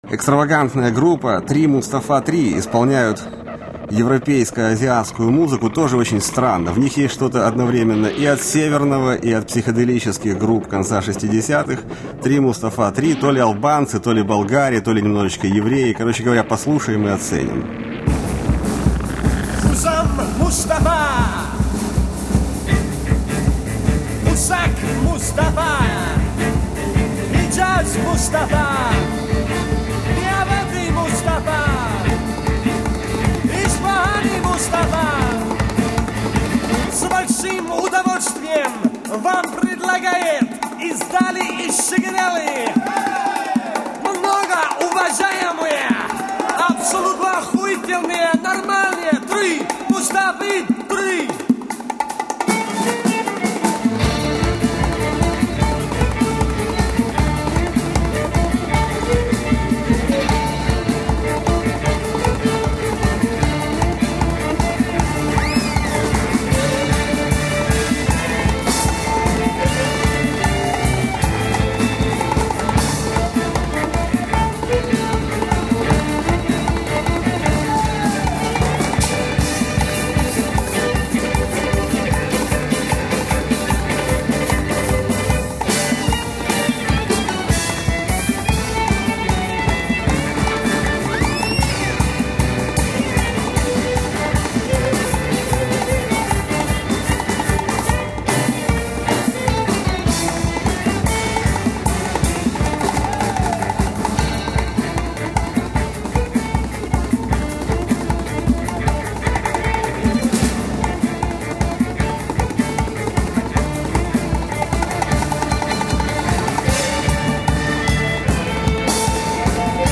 Англичане, музыканты-интернационалисты!